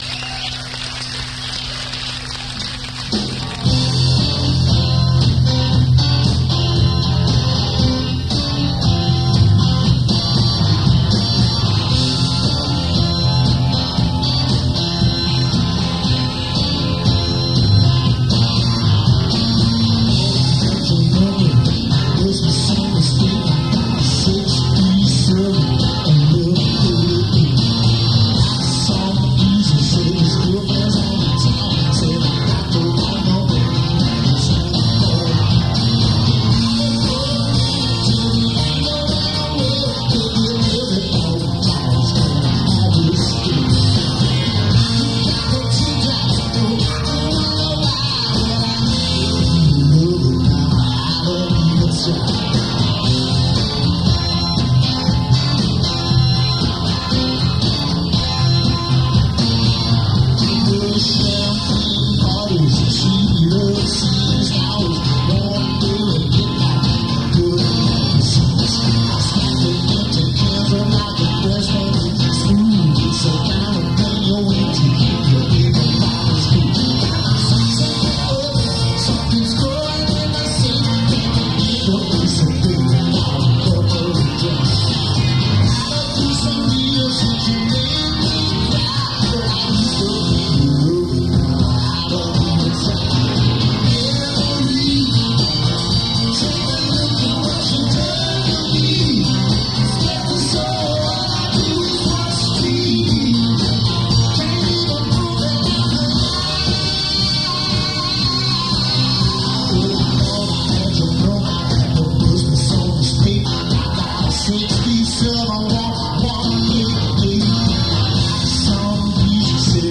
Attn: muddy and tinny sound